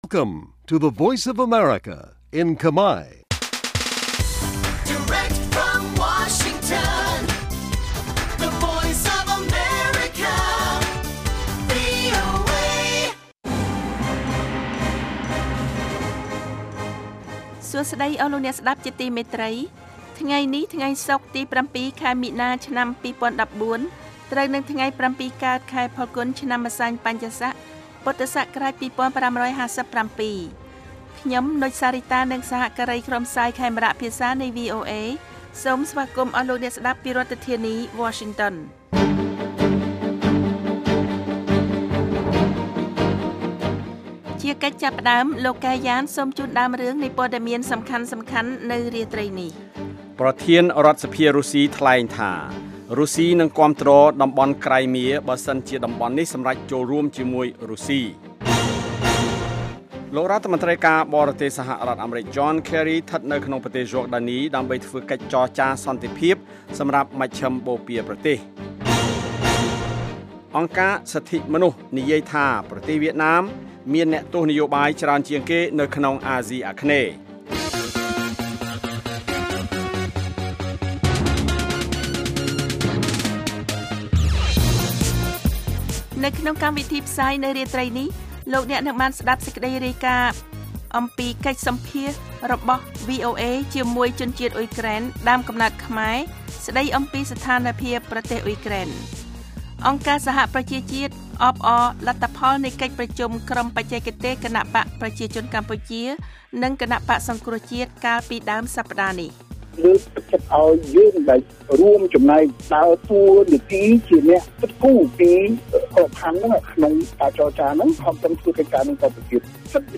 នេះជាកម្មវិធីផ្សាយប្រចាំថ្ងៃតាមវិទ្យុ ជាភាសាខ្មែរ រយៈពេល ៦០ នាទី ដែលផ្តល់ព័ត៌មានអំពីប្រទេសកម្ពុជានិងពិភពលោក ក៏ដូចជាព័ត៌មានពិពណ៌នា ព័ត៌មានអត្ថាធិប្បាយ កម្មវិធីតន្ត្រី កម្មវិធីសំណួរនិងចម្លើយ កម្មវិធីហៅចូលតាមទូរស័ព្ទ និង បទវិចារណកថា ជូនដល់អ្នកស្តាប់ភាសាខ្មែរនៅទូទាំងប្រទេសកម្ពុជា។ កាលវិភាគ៖ ប្រចាំថ្ងៃ ម៉ោងផ្សាយនៅកម្ពុជា៖ ៨:៣០ យប់ ម៉ោងសកល៖ ១៣:០០ រយៈពេល៖ ៦០នាទី ស្តាប់៖ សំឡេងជា MP3